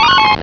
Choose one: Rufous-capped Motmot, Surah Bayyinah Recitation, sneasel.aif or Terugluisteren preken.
sneasel.aif